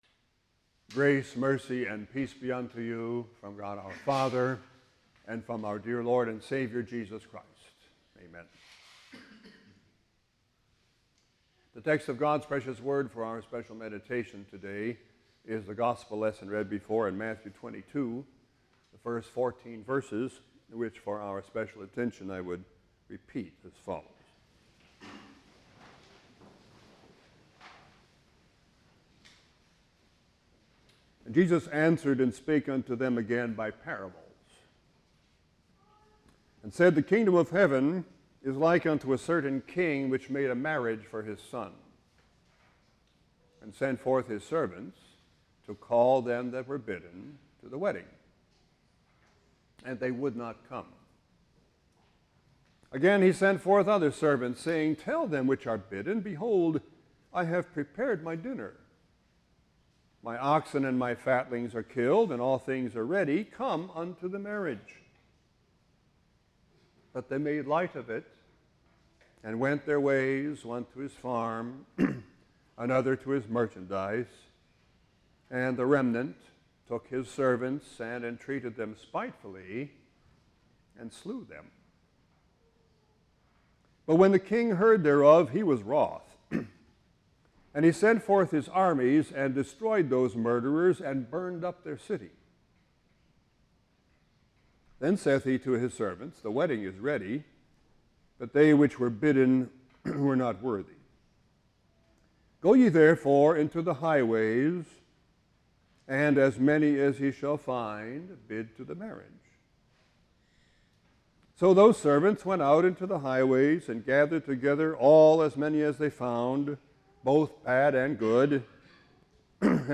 Peace Evangelical Lutheran Church - Why Are Not All Men Saved Eternally?